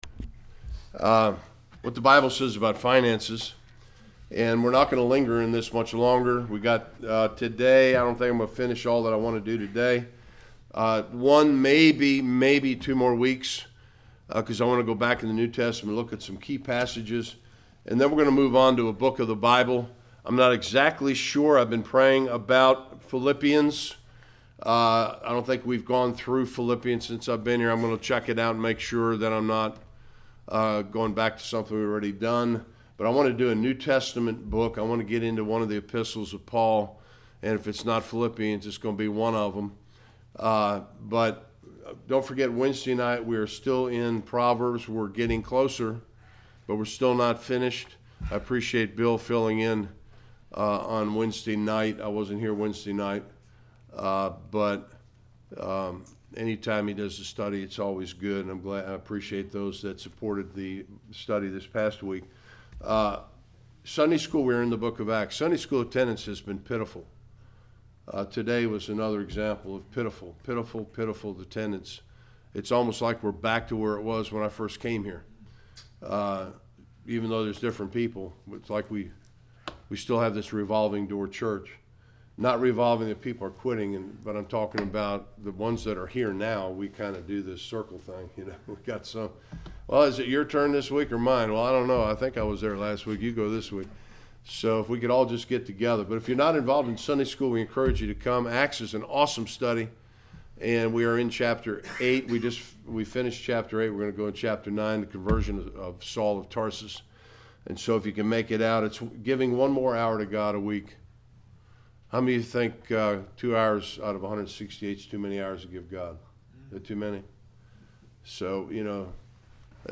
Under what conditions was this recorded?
Service Type: Sunday Worship